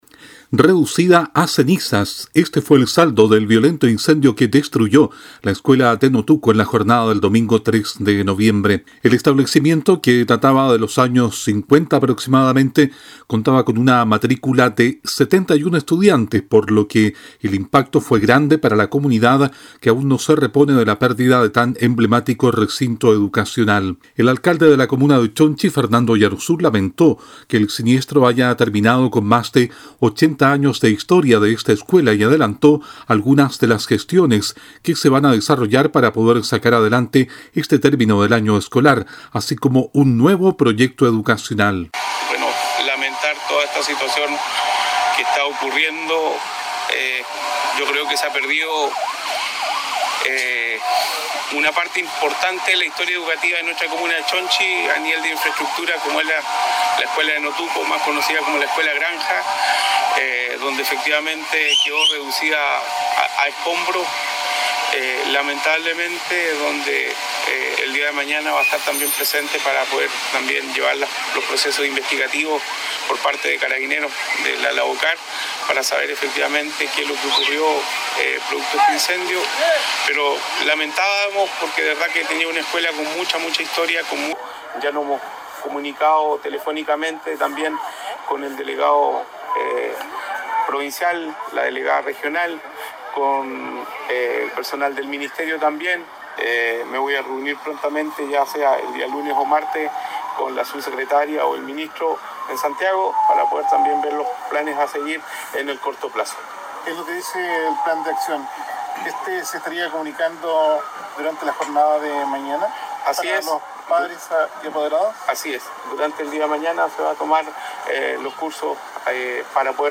El detalle en el siguiente despacho.